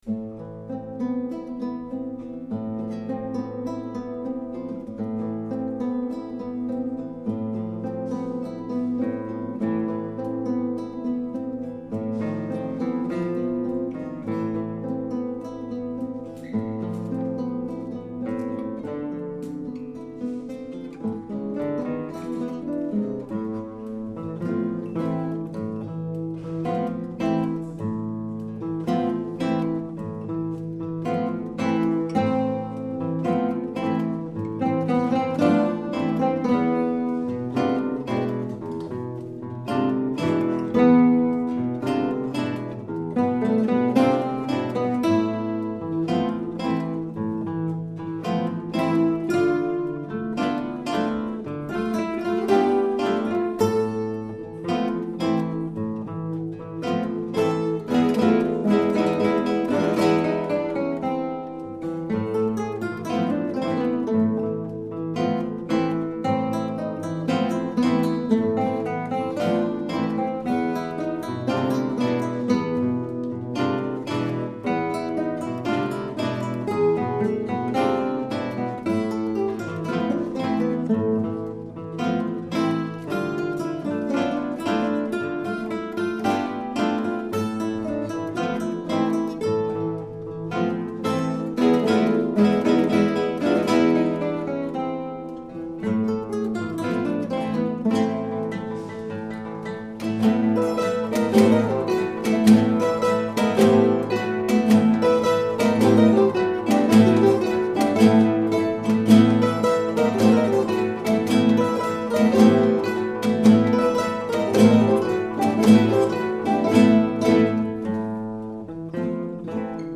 The WGS Guitar Orchestra played and recorded "Tango Estampie" by Luq Lévesque at the April meeting.
The recording above represents only about our third play-through of the Tango, if I remember rightly. Keep in mind that the orchestra consisted of players of all levels of ability - no more than two or three of whom had seen or played their part before (we'll get back to that.)
For "The Floating Ancillary Ants" in April we had 15 players; this time we had 10.